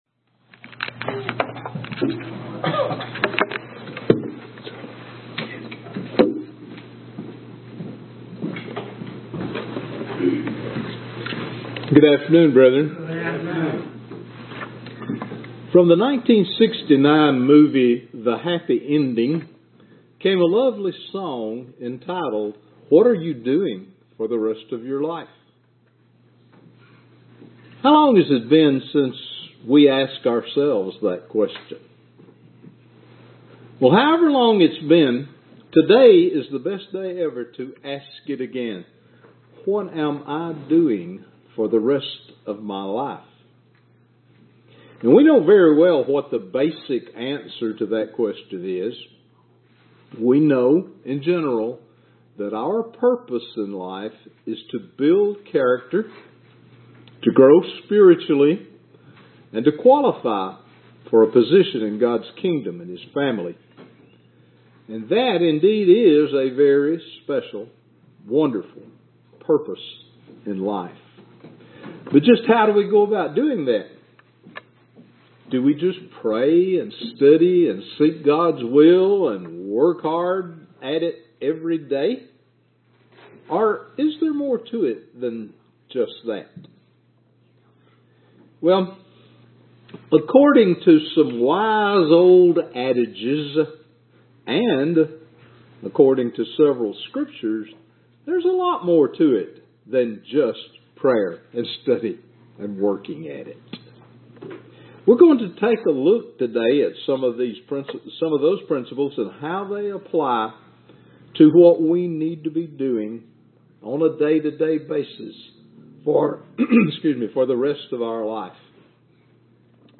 Sermons
Given in Birmingham, AL